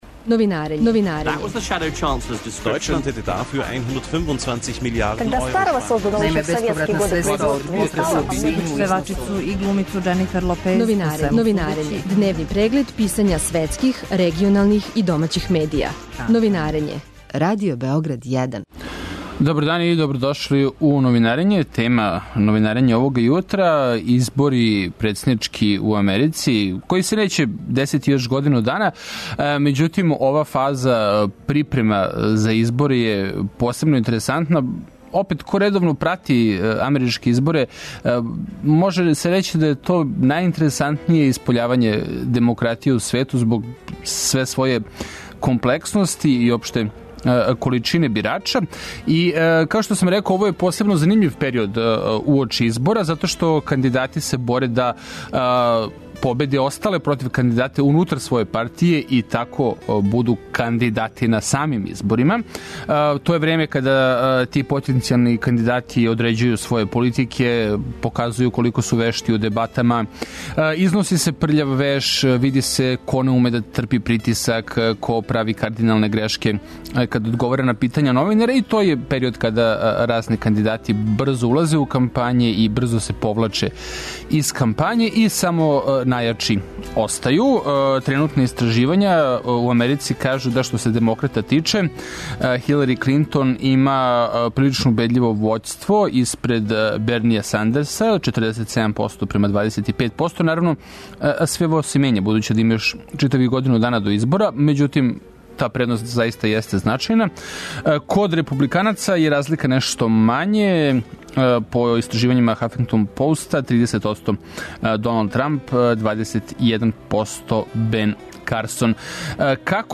Радио Београд 1, 09.40